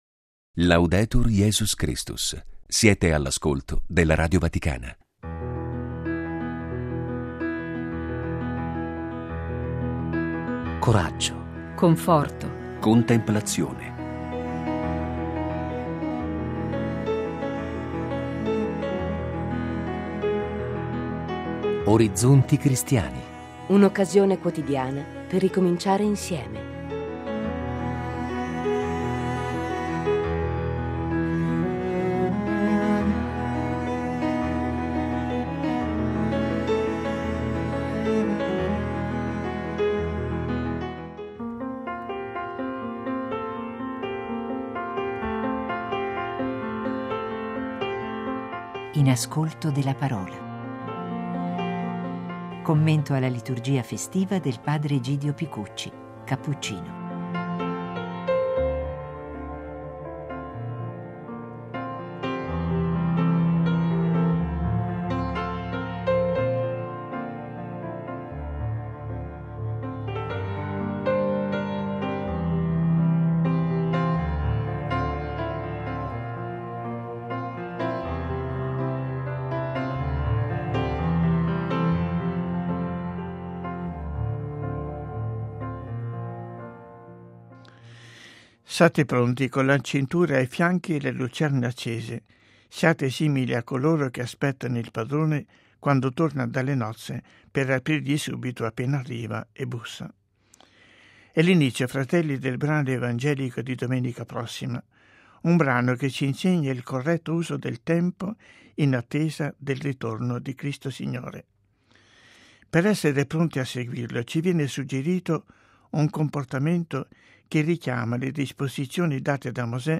commento alla liturgia festiva scritto e proposto al microfono